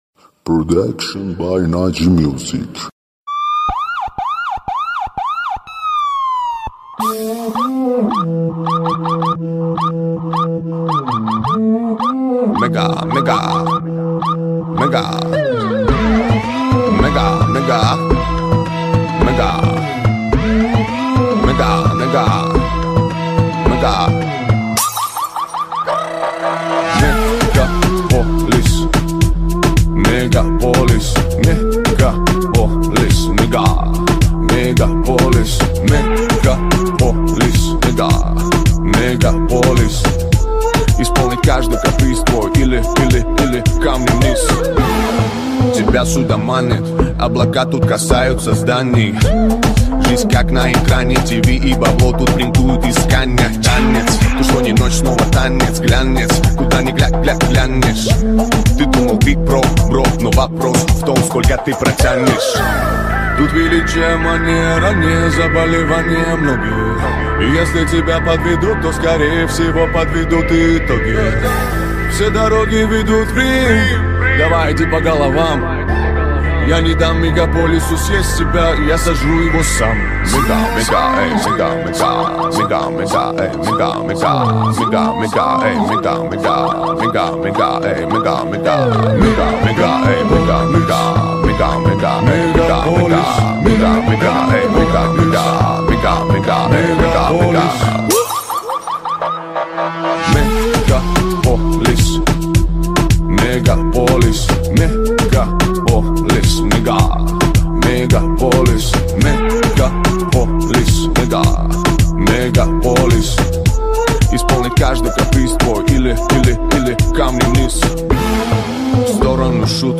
دانلود آهنگ خارجی با صدای آژیر پلیس ریمیکس شوتی لاتی بیس دار
آهنگ پلیسی شوتی خفن آژیر پلیس ریمیکس خارجی مگا مگا